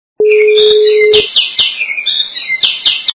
» Звуки » Природа животные » Птичьи трели - лесные звуки
При прослушивании Птичьи трели - лесные звуки качество понижено и присутствуют гудки.
Звук Птичьи трели - лесные звуки